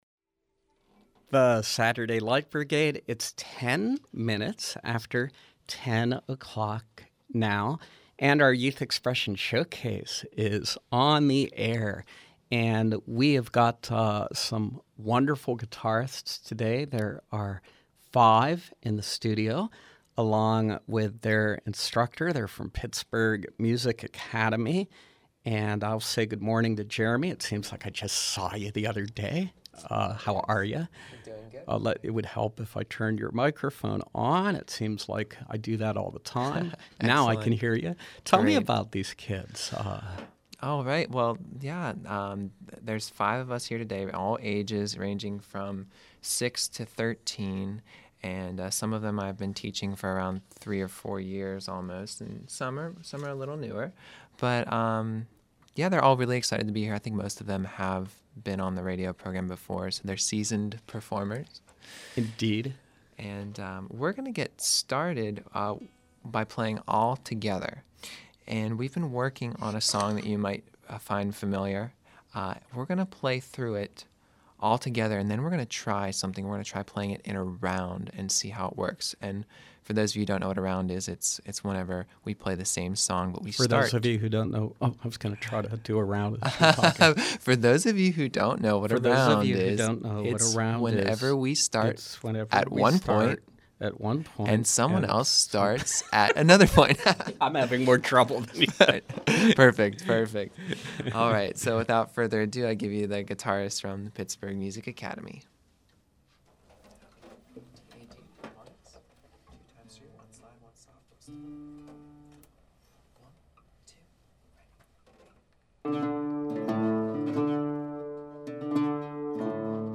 From 4/27/13: Young guitar players from Pittsburgh Music Academy in Carnegie